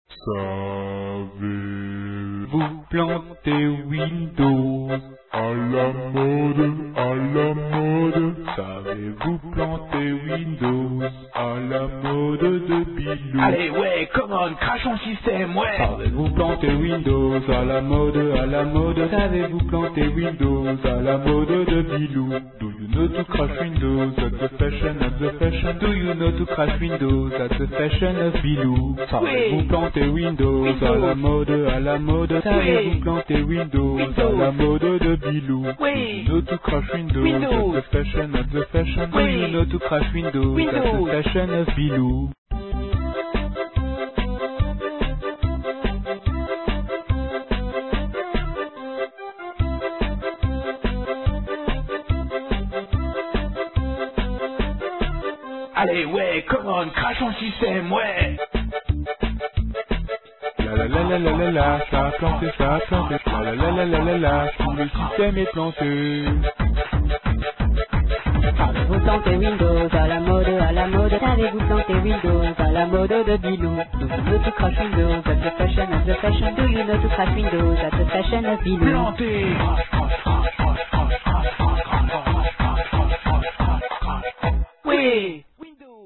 11/12/1999En cadeau de noël, voici en exclusivité pour mes lecteurs favoris et sur un air folklorique bien connu, une petite chanson (130K) de tous les jours (pour ceux qui possèdent un PC tournant sous windows ou assimilé) au format mpeg3: